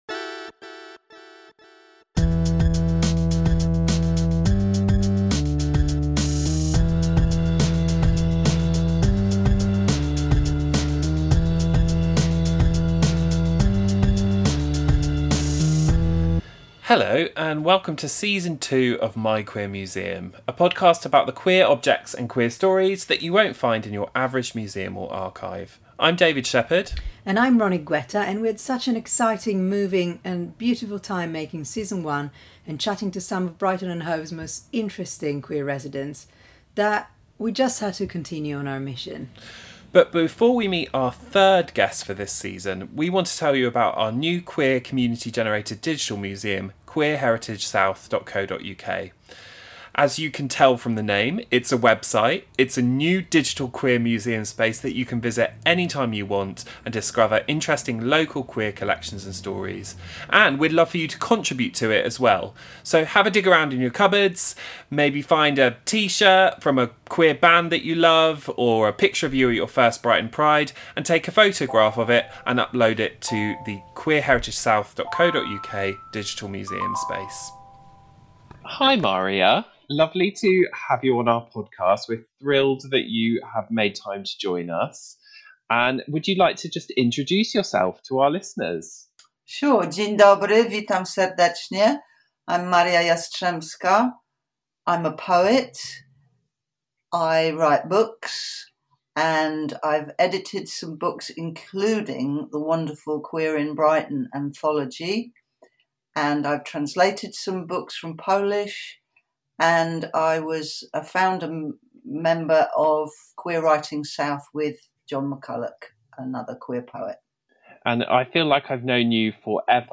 Recorded remotely on April 29, 2021
Interview